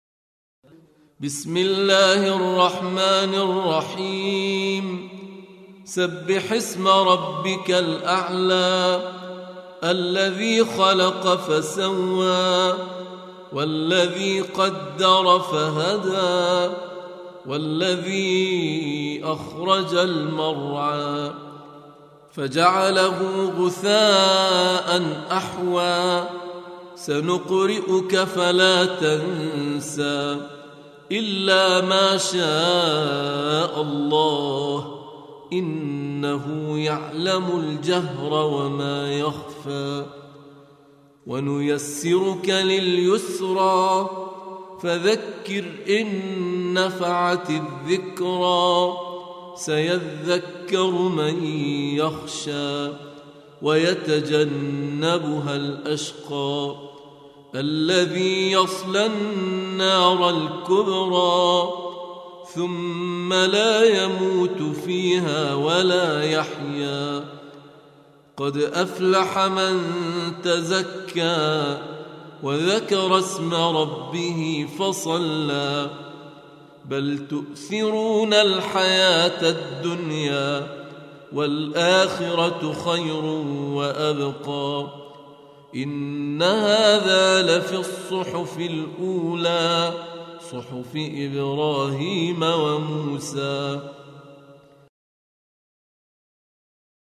سورة الأعلى Audio Quran Tarteel Recitation
Surah Sequence تتابع السورة Download Surah حمّل السورة Reciting Murattalah Audio for 87. Surah Al-A'l� سورة الأعلى N.B *Surah Includes Al-Basmalah Reciters Sequents تتابع التلاوات Reciters Repeats تكرار التلاوات